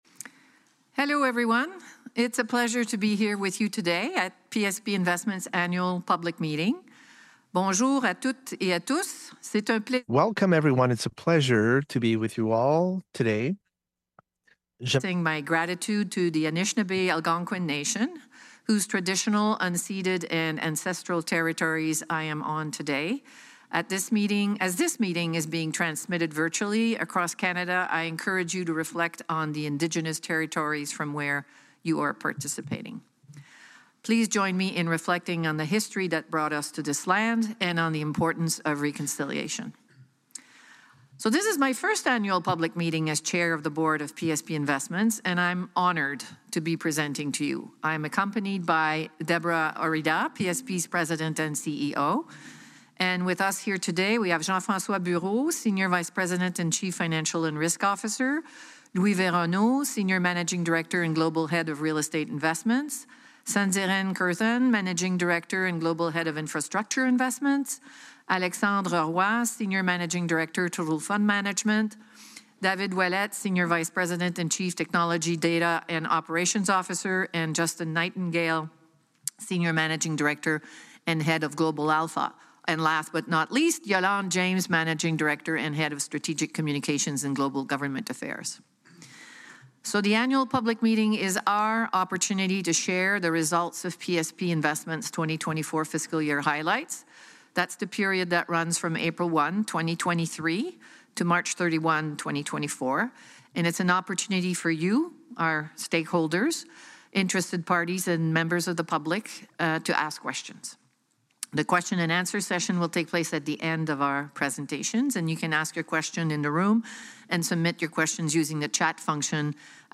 Annual Public Meeting Audio Recording 2024